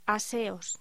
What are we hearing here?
Locución: Aseos